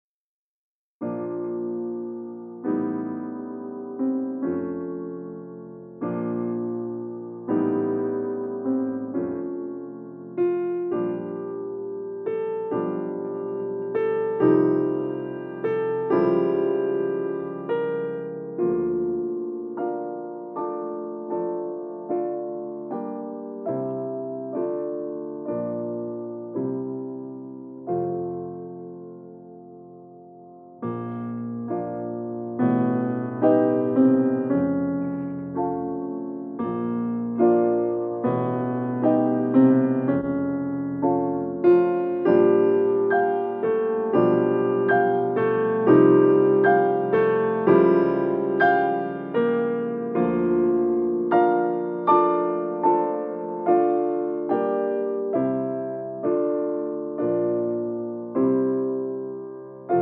Lent et grave   3:24